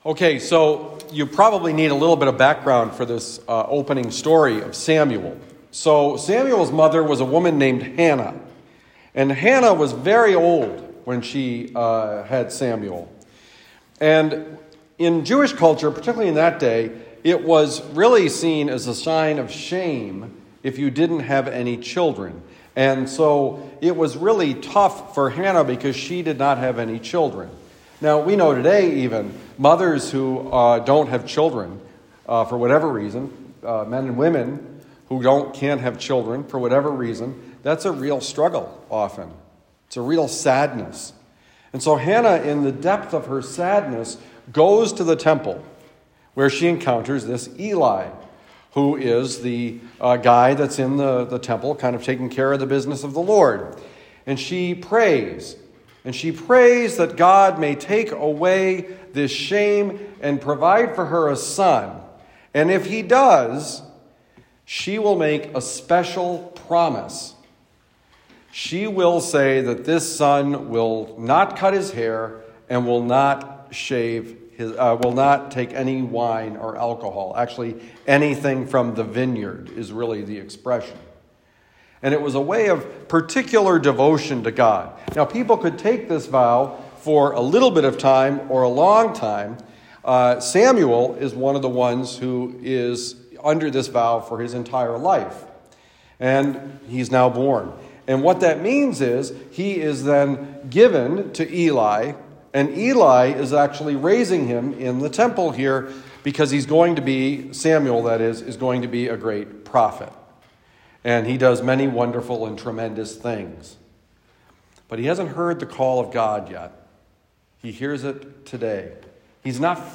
Homily given at the Lasalle Retreat Center, Glencoe, Missouri.